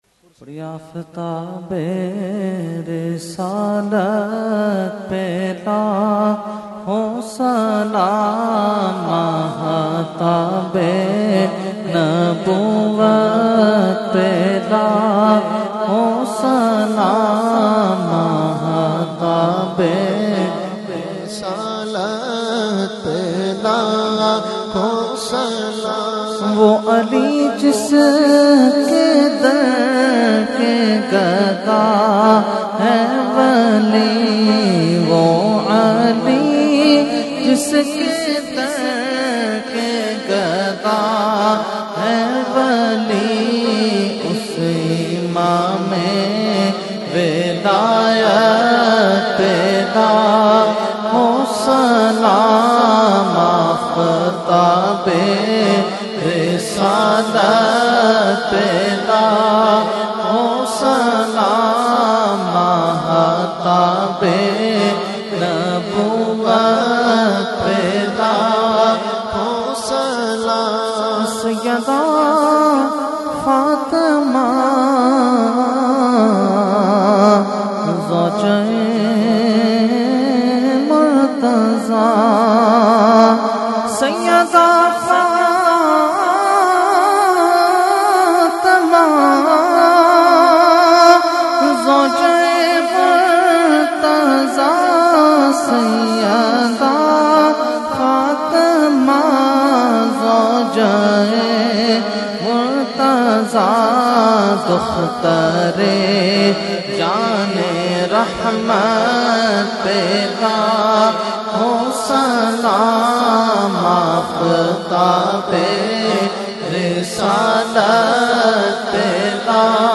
held on 1,2,3 January 2021 at Dargah Alia Ashrafia Ashrafabad Firdous Colony Gulbahar Karachi.
Category : Salam | Language : UrduEvent : Urs Qutbe Rabbani 2021